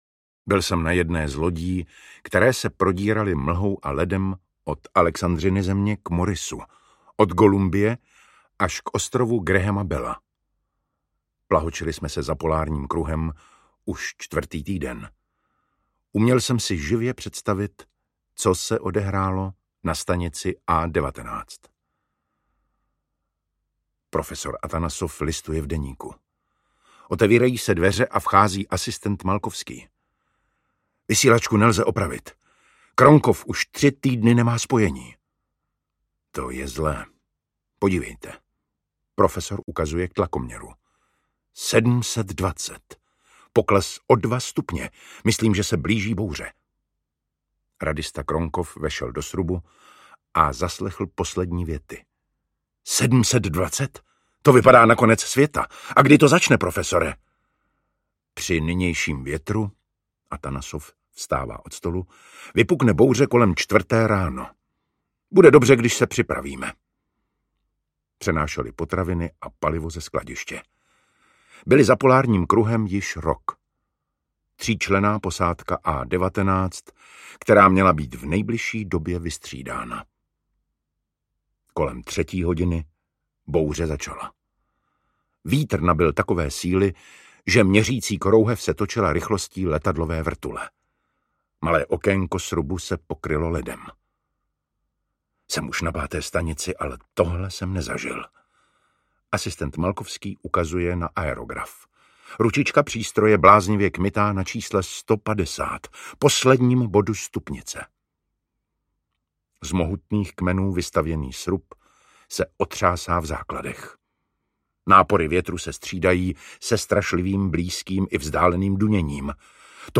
Ukázka z knihy
Čte David Matásek.
Vyrobilo studio Soundguru.